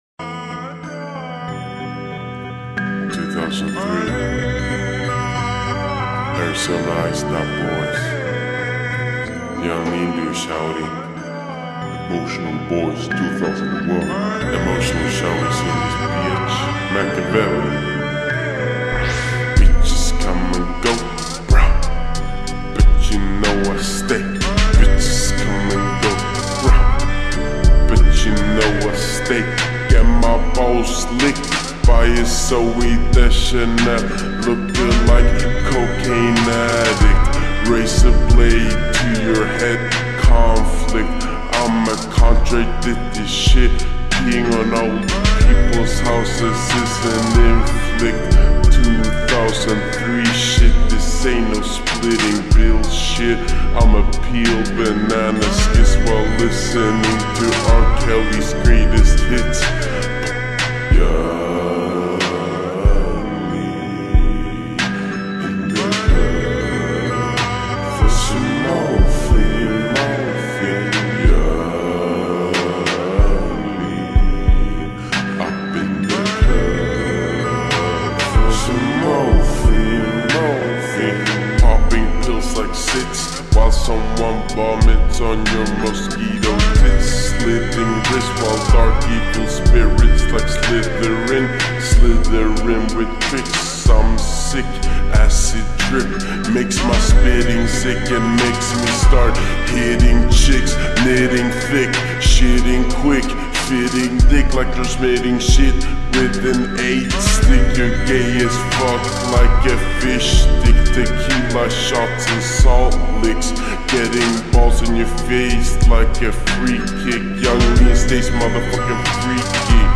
با ریتمی کند شده
شاد